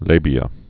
(lābē-ə)